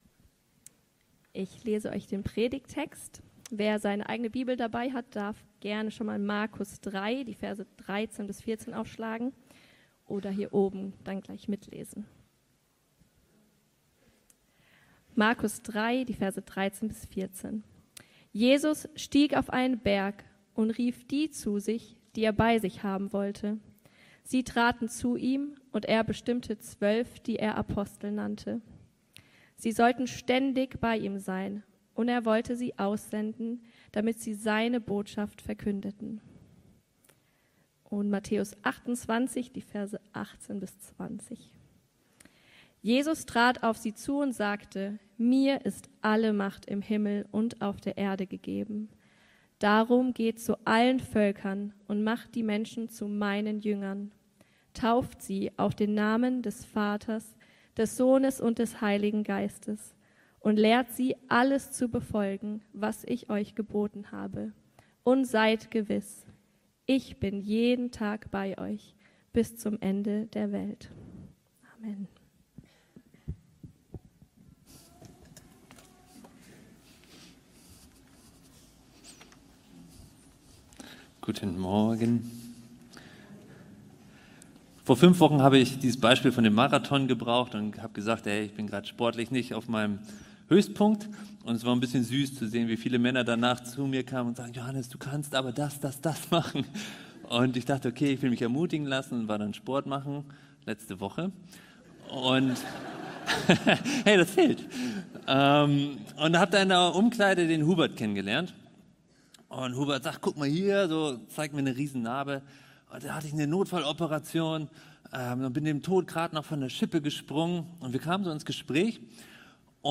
Predigten – Er-lebt.